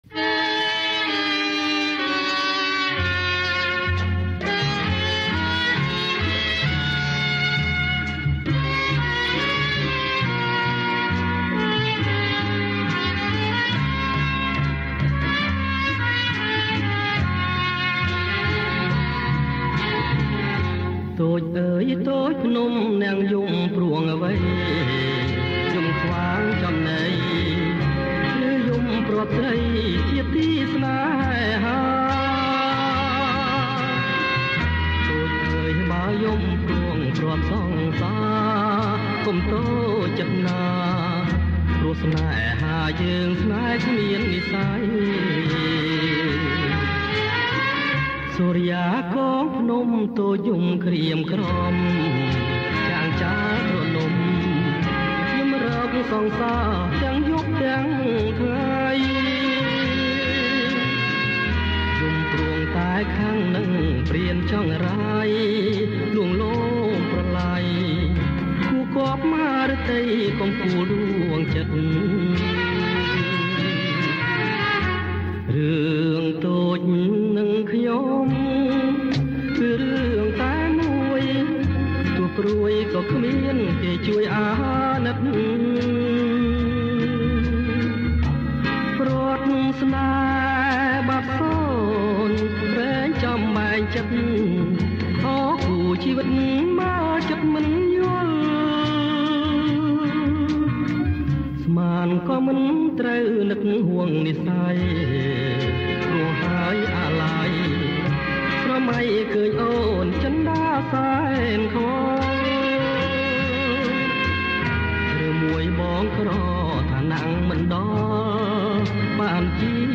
• ប្រគំជាចង្វាក់ Bolero Lent